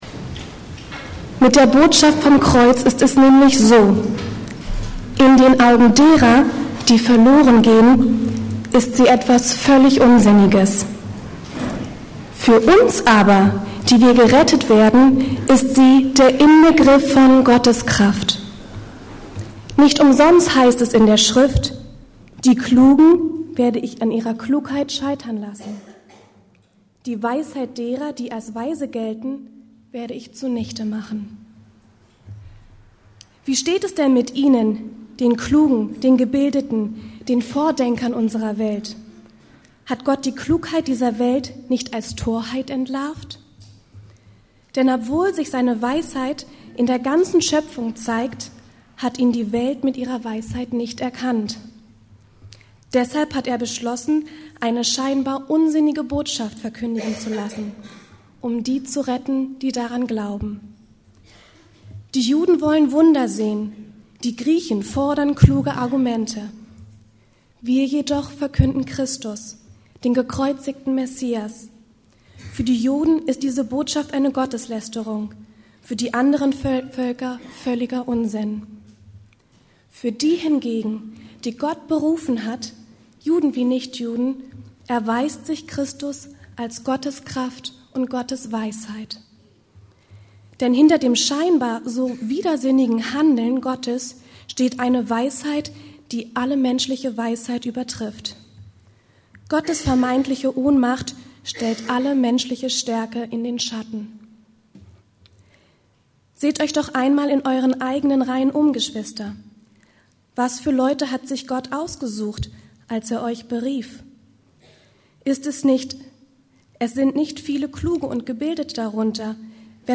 Das Wort vom Kreuz- ein Witz, ein Skandal und eine Kraftquelle ~ Predigten der LUKAS GEMEINDE Podcast